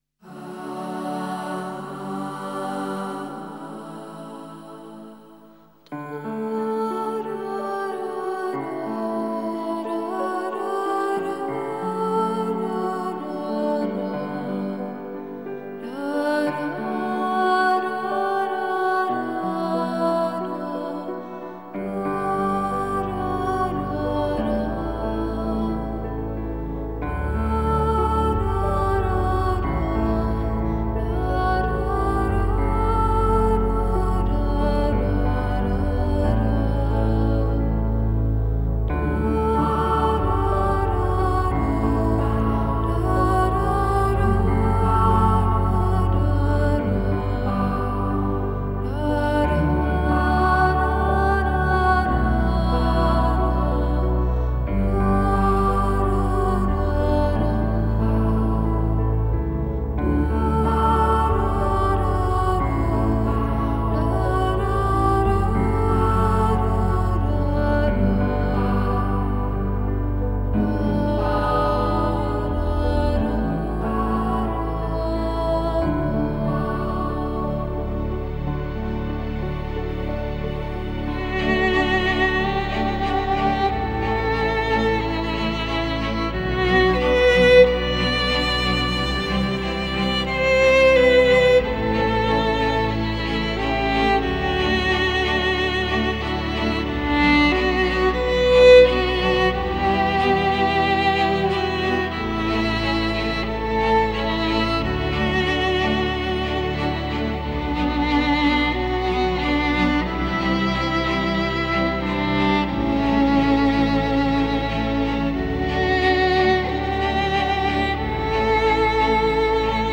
장르: Electronic
스타일: Modern Classical, Minimal, Ambient